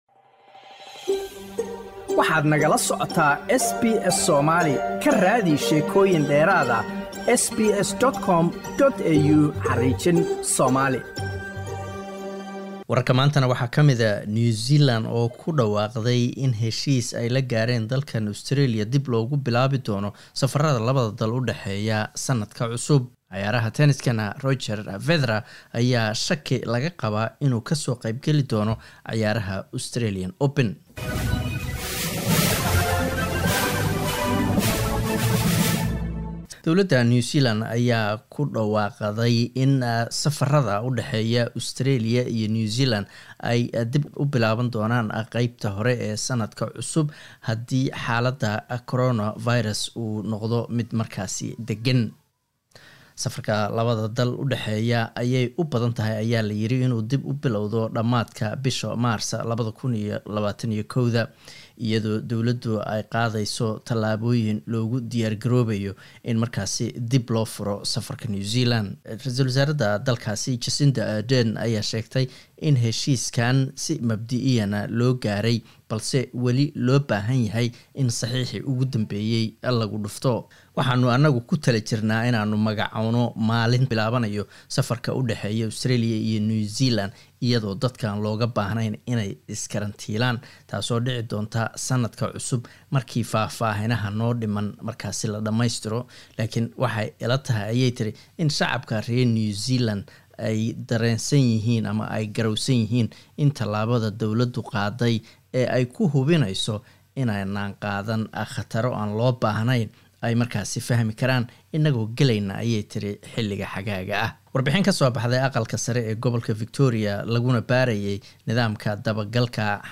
Wararka SBS Somali Isniin 14 desember